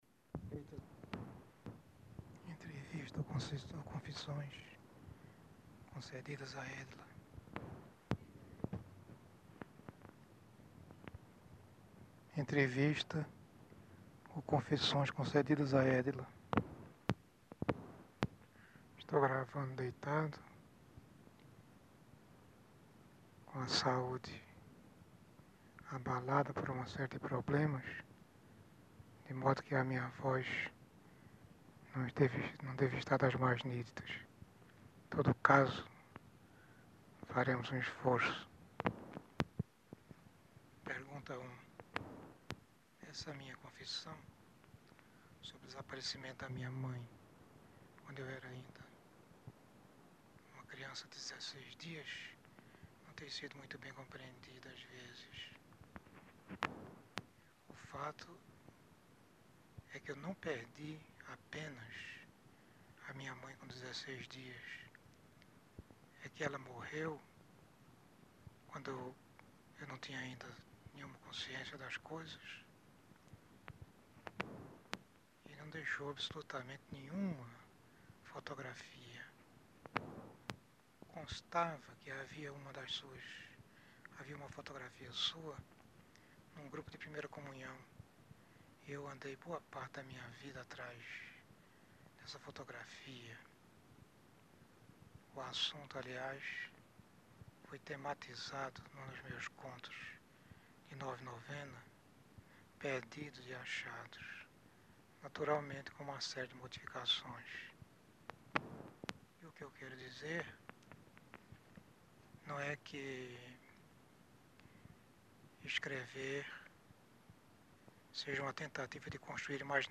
Dentre o conjunto documental depositado no Instituto de Estudos Brasileiros, destaca-se um conjunto de fitas K7.
Com a duração de quase quatro minutos de fala, a fita traz a inscrição manuscrita “Fala da mãe” e guarda a voz do escritor narrando o que se julgou inicialmente ser um depoimento de Osman Lins, em que novamente abordará a figura da mãe:
A fita, que comportaria mais de 60 minutos de gravação, traz apenas este breve trecho. Com forte teor emocional, o início da fala do escritor traz a palavra “confissões”, não de forma ornamental[4].
Os registros que marcam as interrupções da fita foram mantidos na transcrição, pois se acredita que eles são mais uma evidência da fragilidade de saúde na qual se encontrava o escritor.